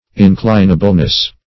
Search Result for " inclinableness" : The Collaborative International Dictionary of English v.0.48: Inclinableness \In*clin"a*ble*ness\, n. The state or quality of being inclinable; inclination.